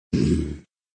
SZombieWarn.ogg